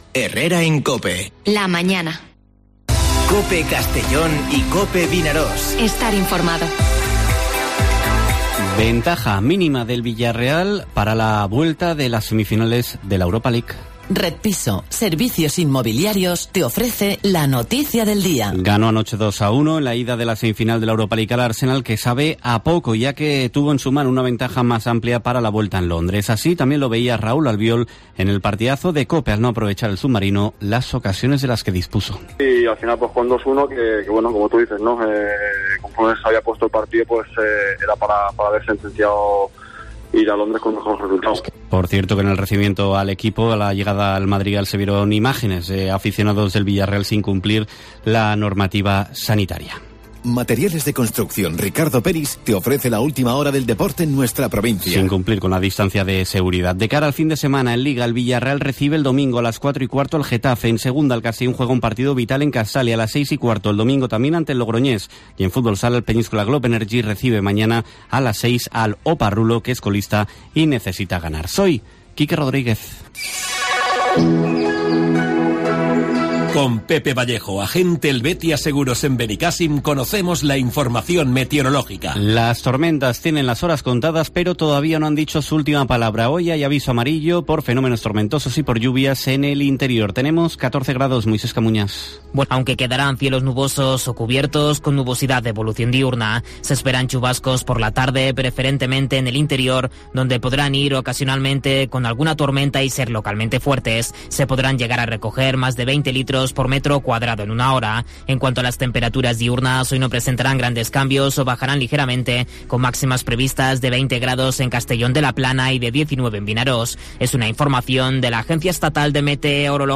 Informativo Herrera en COPE en la provincia de Castellón (30/04/2021)